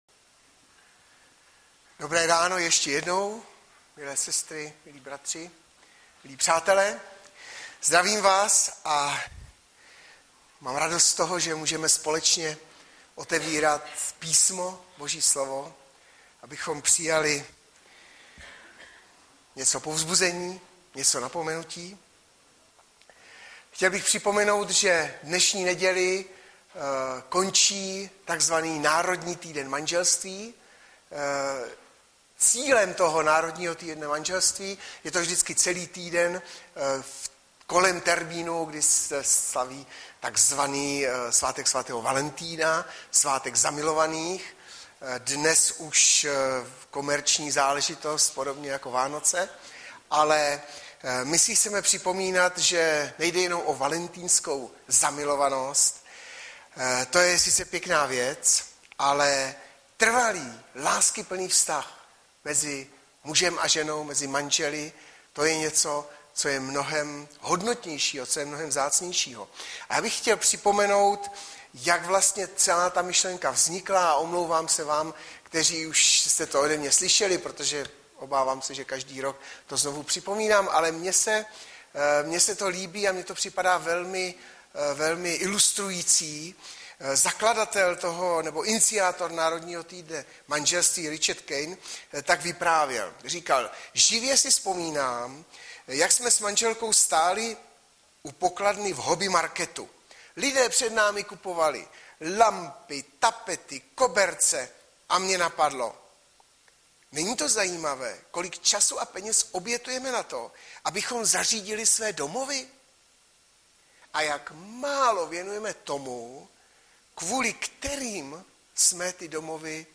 Webové stránky Sboru Bratrské jednoty v Litoměřicích.
Kázání